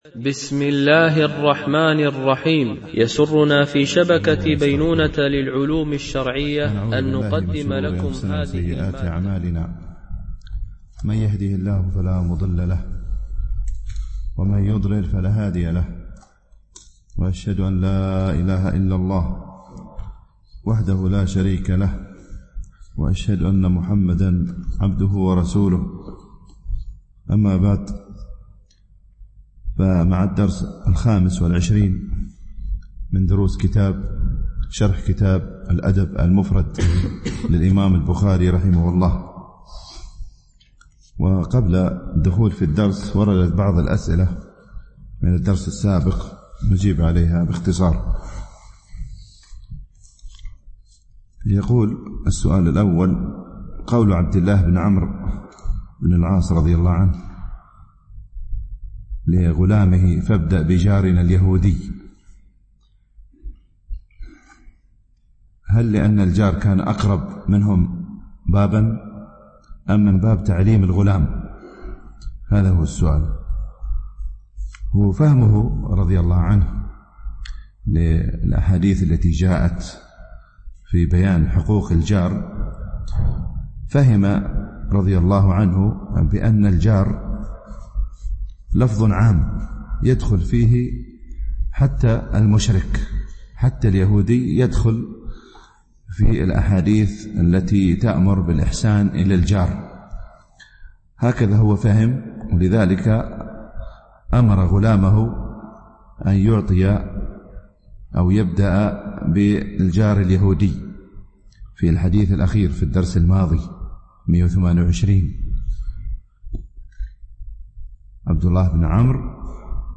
شرح الأدب المفرد للبخاري ـ الدرس 25 ( الحديث 129-131 )